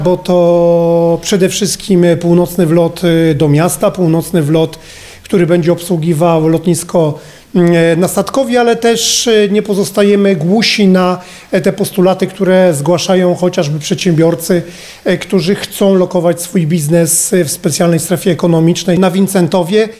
Nowo przebudowana ulica ma zapewnić maksimum komfortu i bezpieczeństwa użytkownikom ruchuTo dla nas bardzo ważna inwestycja, mówi prezydent miasta Radosław Witkowski: